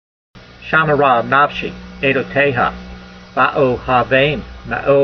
v167_voice.mp3